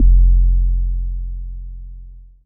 LOHICUT BASS.wav